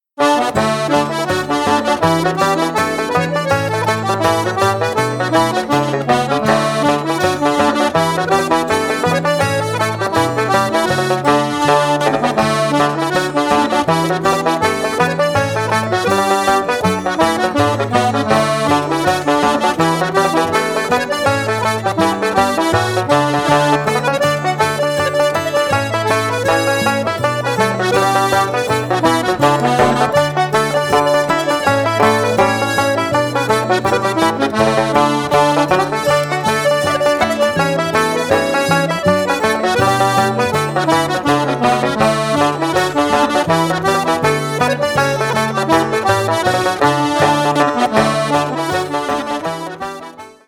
Straight down the line Irish traditional accordion music